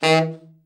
TENOR SN   9.wav